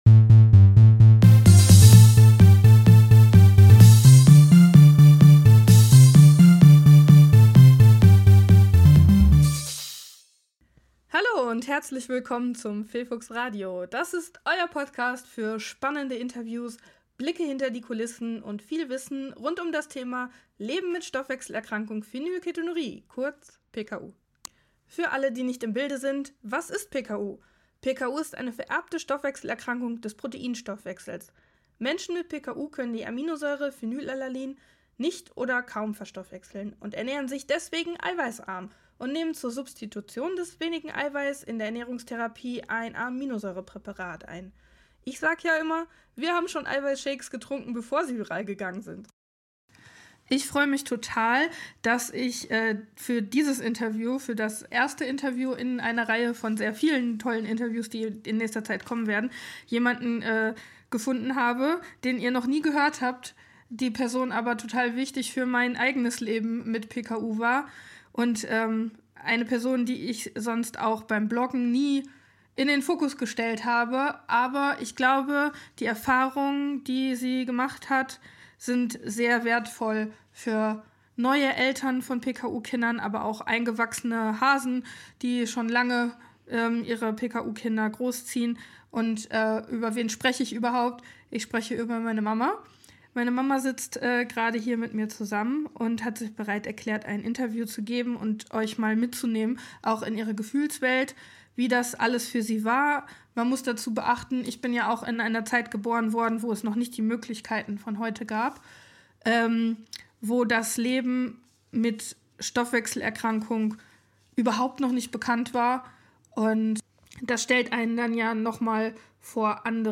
002 Das erste Interview ~ Phefux Radio - der PKU Podcast